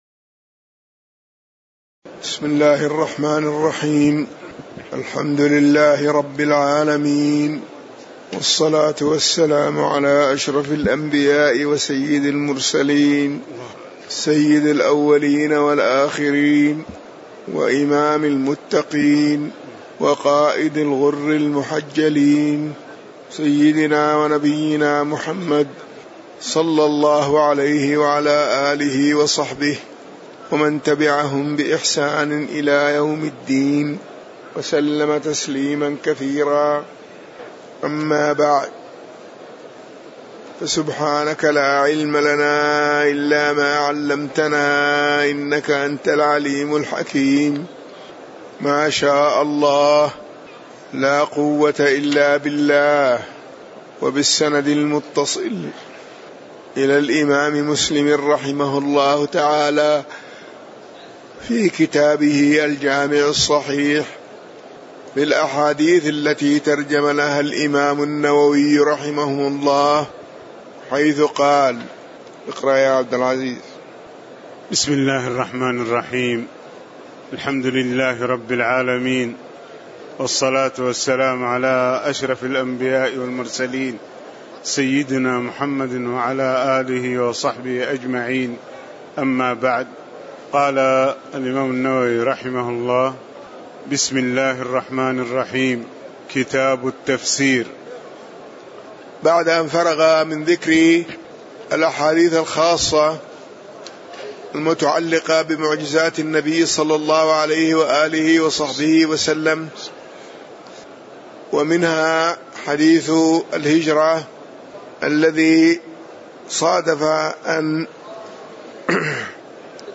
تاريخ النشر ٣ رمضان ١٤٣٨ هـ المكان: المسجد النبوي الشيخ